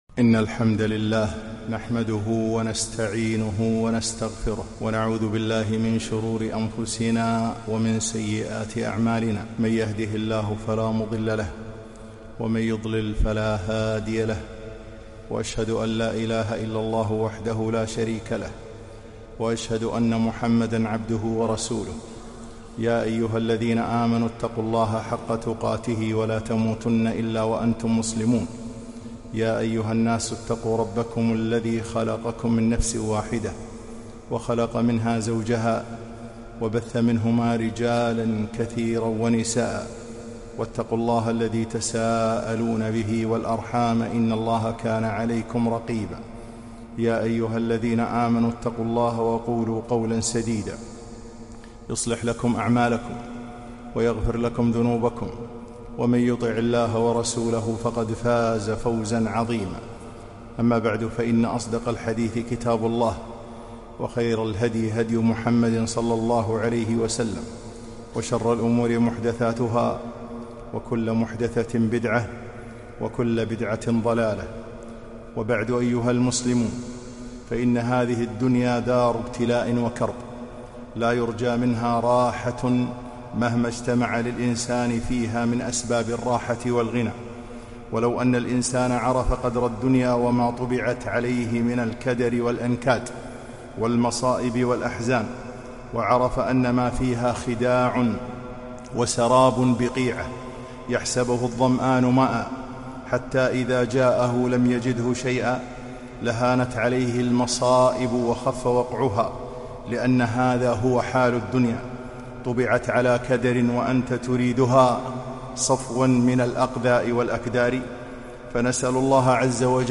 خطبة - تسليةُ الصابرين